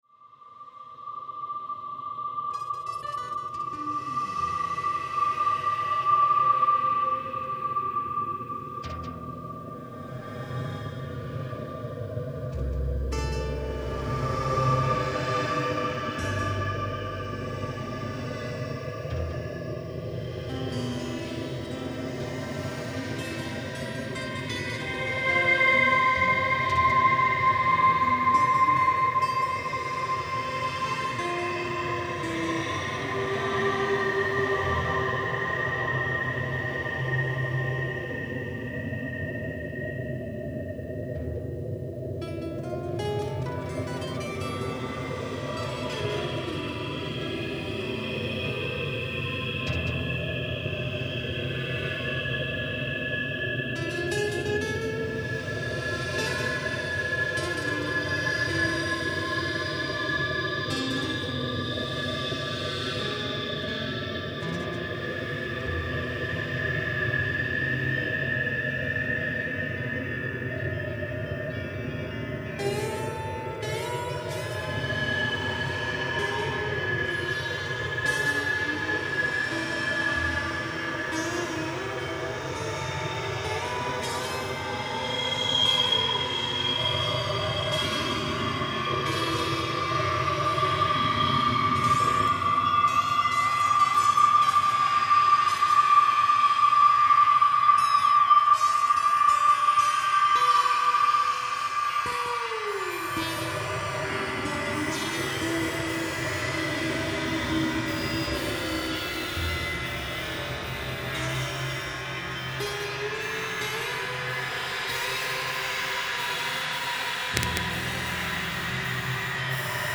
A lot of what you hear in the above pieces is from his own setup.
guitars and guitar processing
laptop